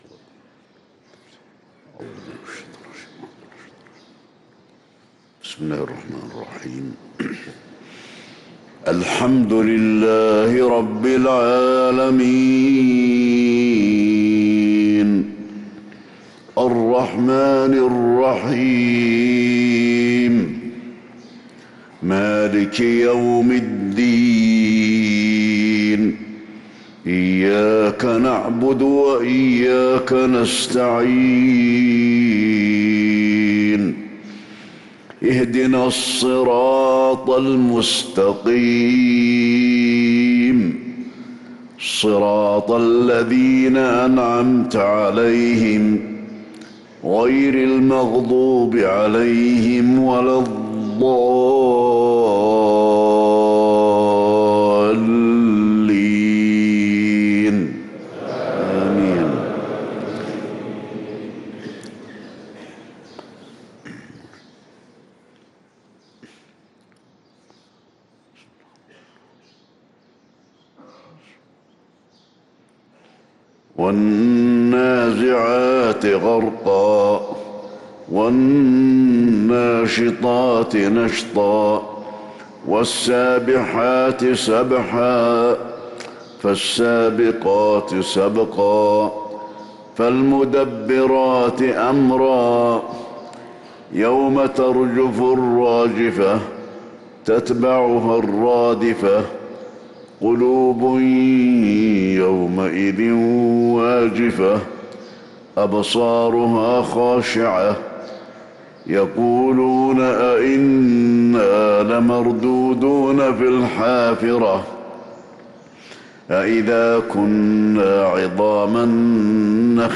صلاة الفجر للقارئ علي الحذيفي 23 ربيع الأول 1445 هـ
تِلَاوَات الْحَرَمَيْن .